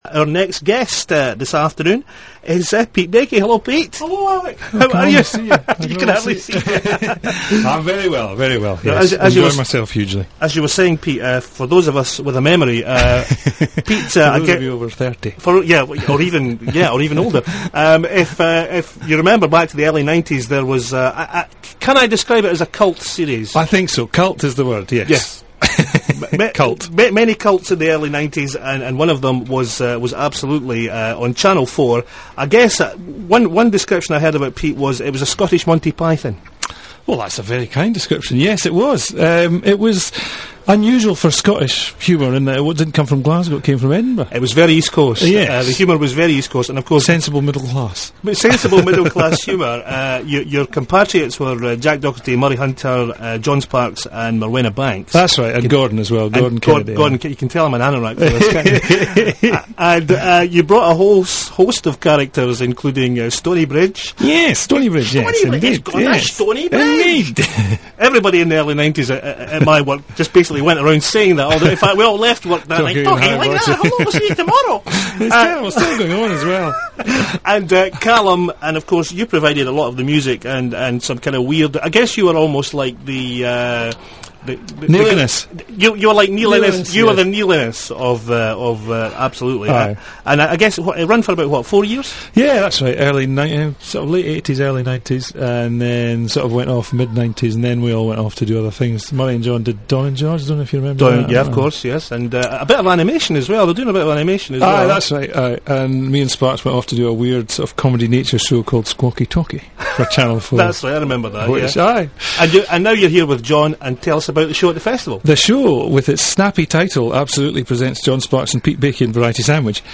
All MP3's are recorded at 22KHz, 64KBps, Stereo.
Transmitted on Festival FM 27th Aug 2005.